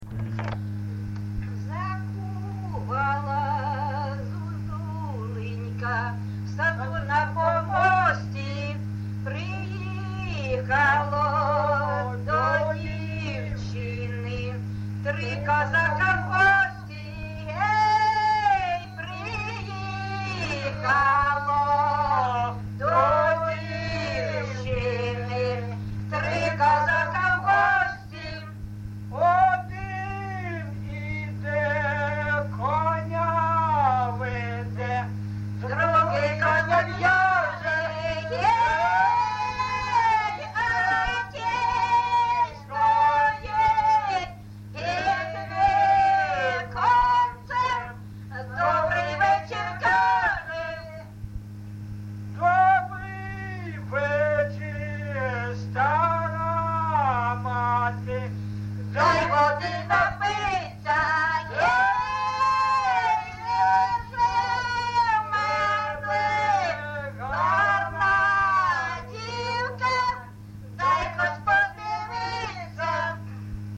ЖанрПісні з особистого та родинного життя, Козацькі
Місце записус. Бузова Пасківка, Полтавський район, Полтавська обл., Україна, Полтавщина